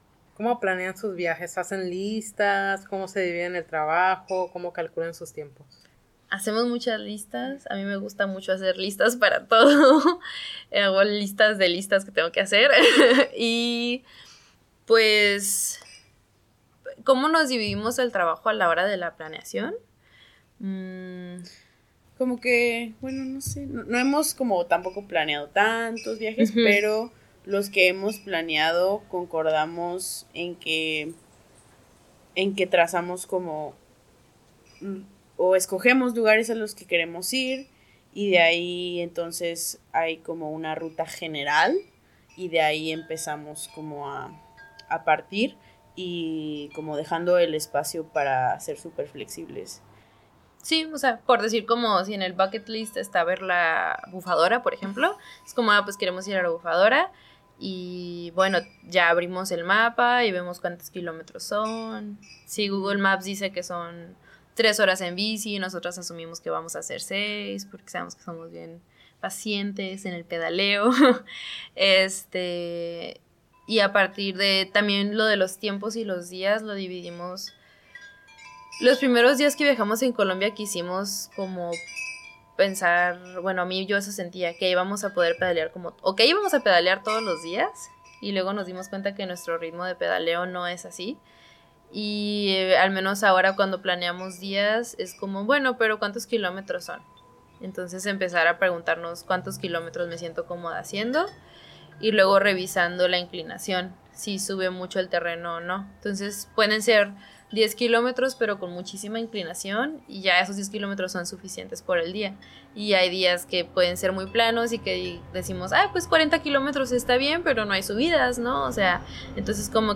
Entrevista a lesbianas mochileras en bici p2